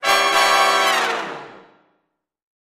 Music Effect; Big Jazz Band Hits.